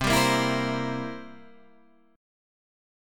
C7 Chord (page 2)
Listen to C7 strummed